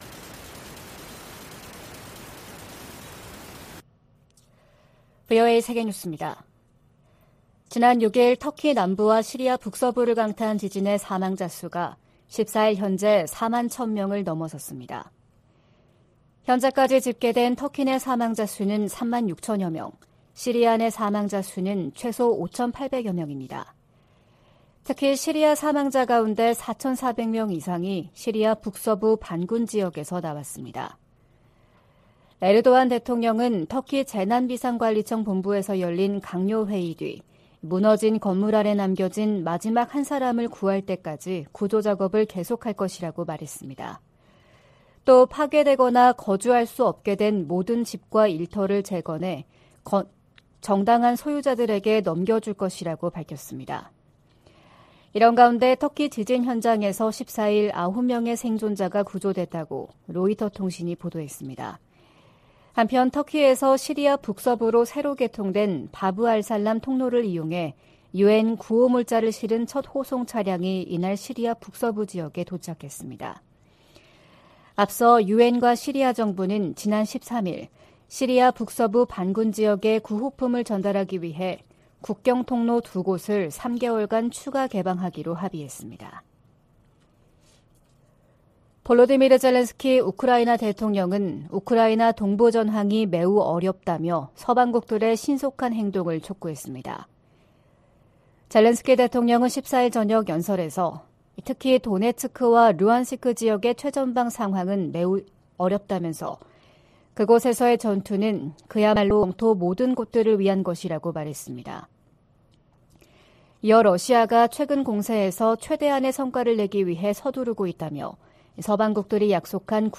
VOA 한국어 '출발 뉴스 쇼', 2023년 2월 16일 방송입니다. 미 국무부가 중국의 정찰풍선 문제를 거론하며, 중국을 미한일 3국의 역내 구상을 위협하는 대상으로 규정했습니다. 백악관은 중국의 정찰풍선 프로그램이 정부의 의도와 지원 아래 운용됐다고 지적했습니다. 북한은 고체연료 ICBM 부대를 창설하는 등, 핵무력 중심 군 편제 개편 움직임을 보이고 있습니다.